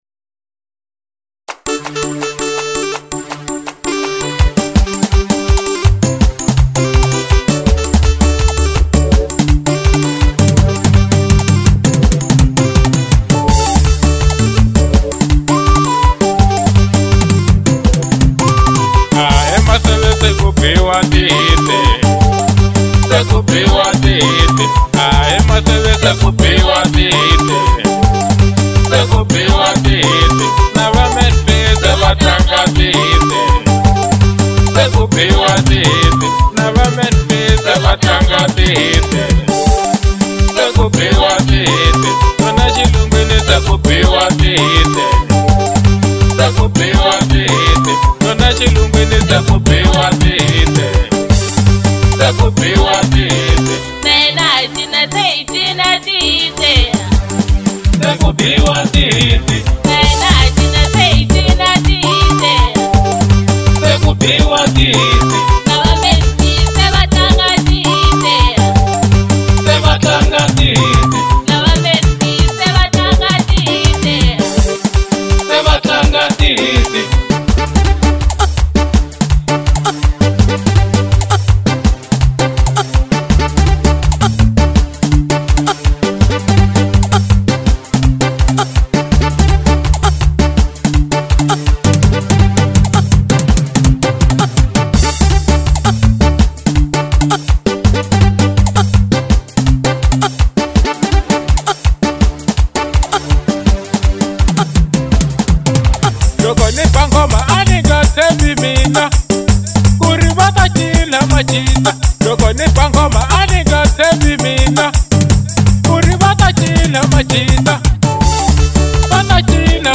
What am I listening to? Genre : Xitsonga